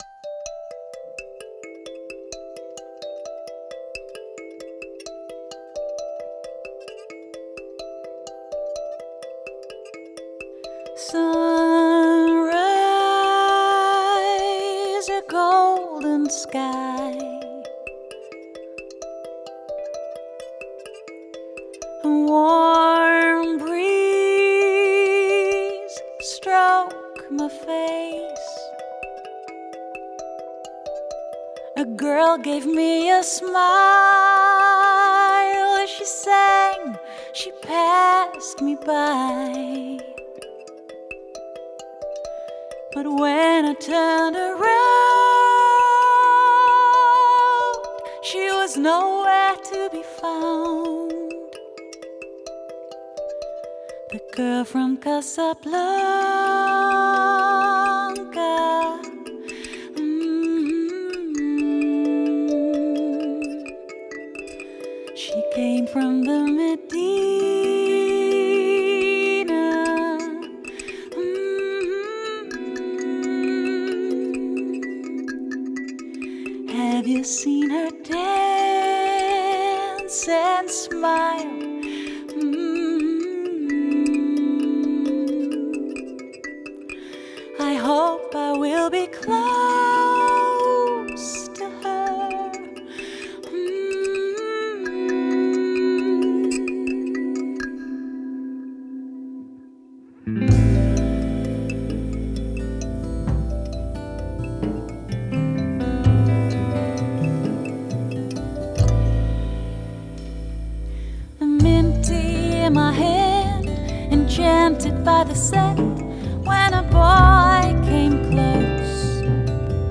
Genre: Jazz/Soul/Pop Vocals